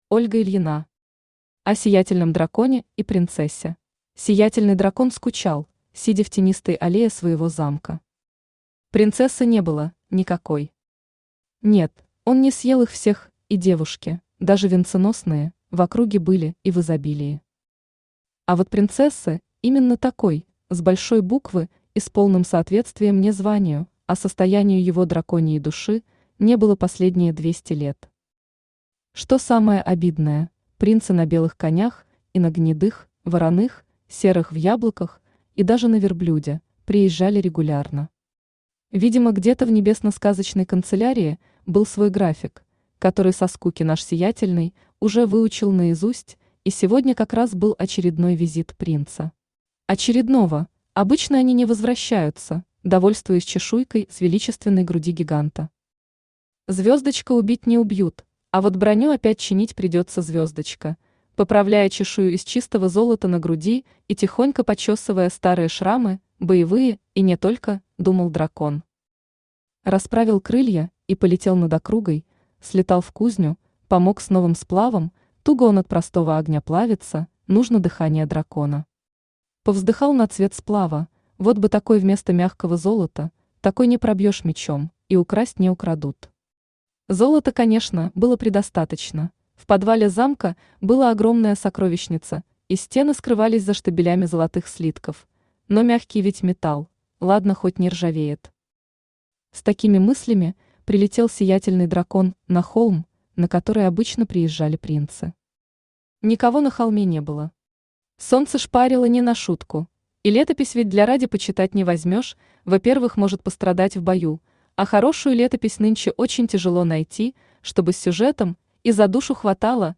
Aудиокнига О Сиятельном Драконе и Принцессе Автор Ольга Тигра Ильина Читает аудиокнигу Авточтец ЛитРес.